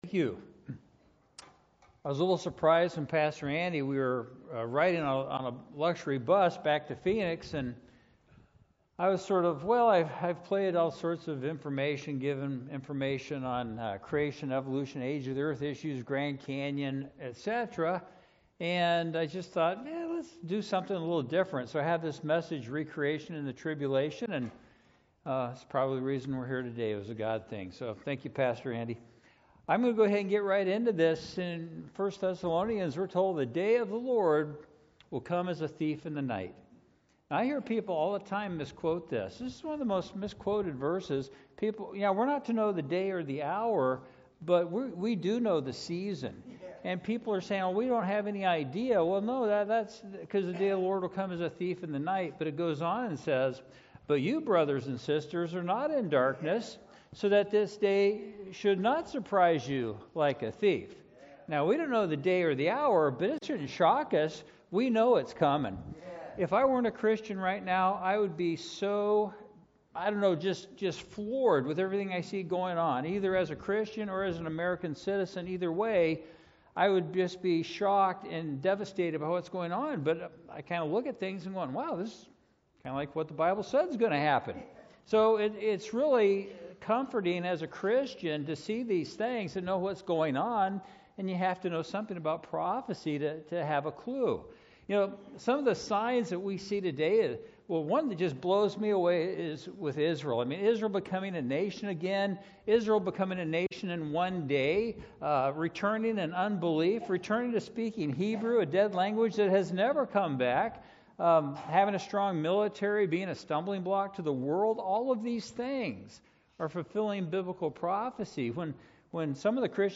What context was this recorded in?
2024 Prophecy Conference